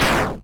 snd_damage.wav